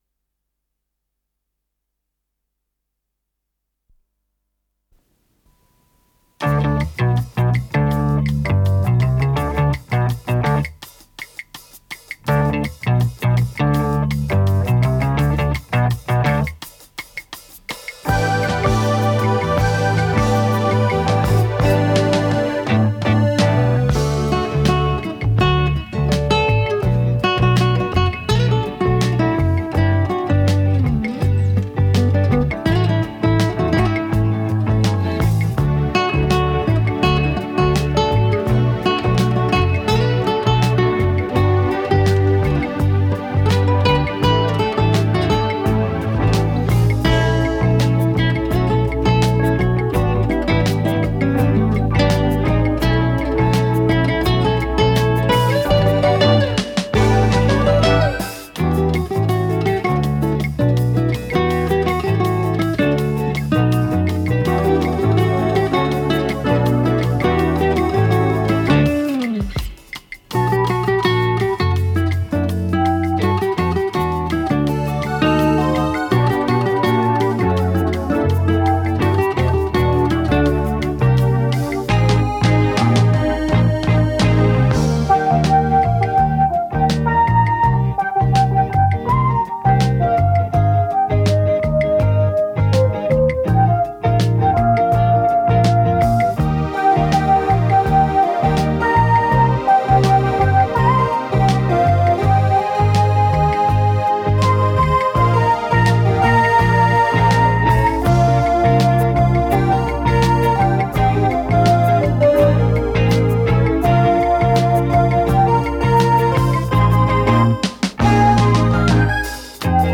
ПодзаголовокПьеса
акустическая гитара